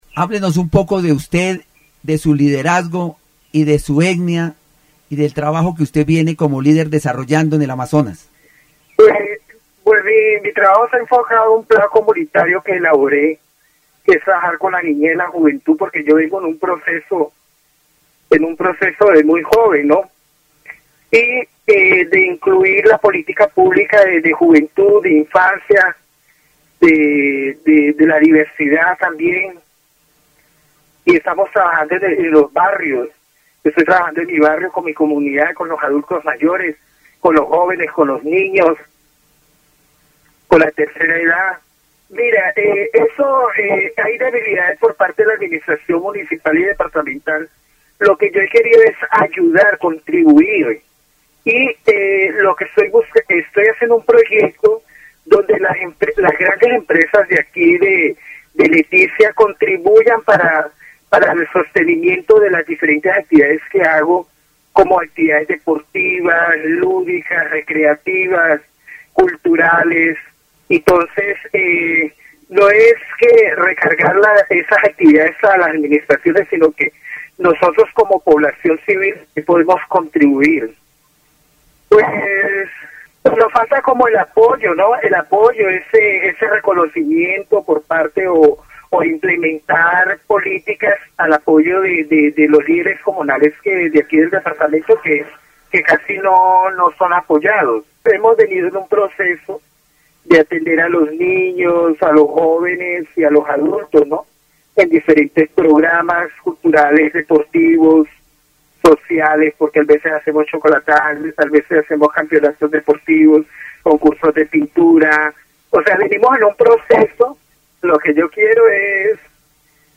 El programa de radio presenta una entrevista con un líder comunitario que trabaja en la región de la Amazonía, enfocándose en el desarrollo de proyectos comunitarios que involucran a jóvenes, niños, adultos mayores y la tercera edad. El líder destaca la importancia de implementar políticas públicas que apoyen a los líderes comunales y fomenten actividades culturales, deportivas y recreativas.
Amazonas (Región, Colombia) -- Grabaciones sonoras , Programas de radio , Liderazgo comunitario , Políticas públicas -- Juventud -- Amazonas (Región, Colombia) , Desarrollo comunitario